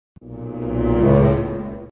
Звуки шока
В коллекции представлены различные варианты: резкие скримеры, звуки удивления, напряженные моменты из фильмов и игр.
Шоковая вставка